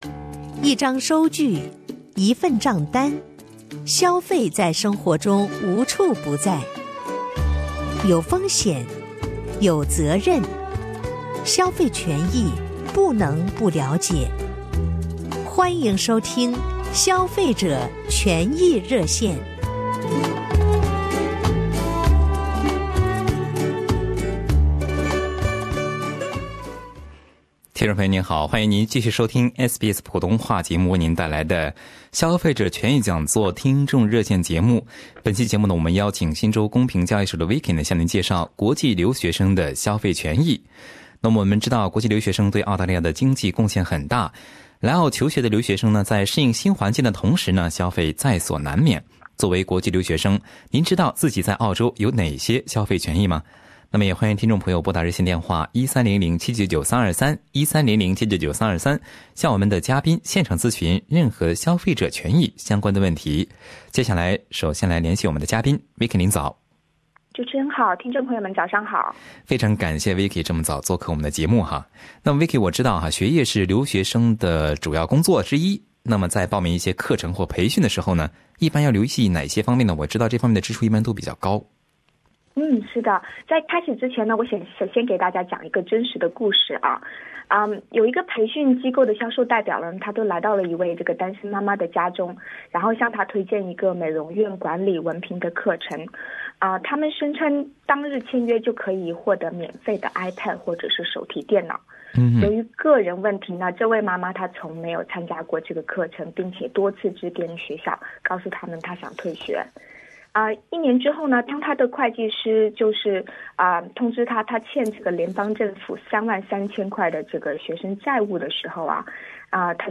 另外，有听众也对我们的节目嘉宾进行了现场热线咨询。